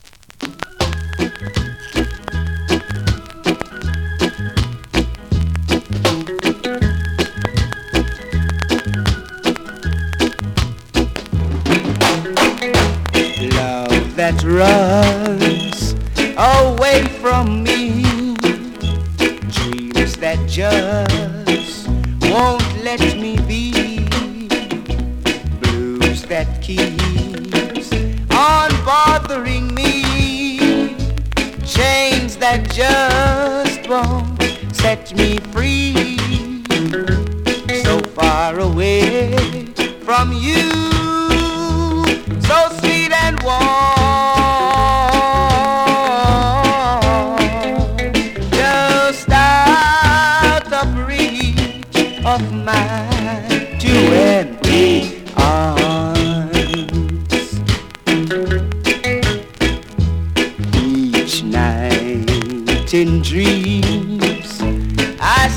SKA〜REGGAE
スリキズ、ノイズそこそこありますが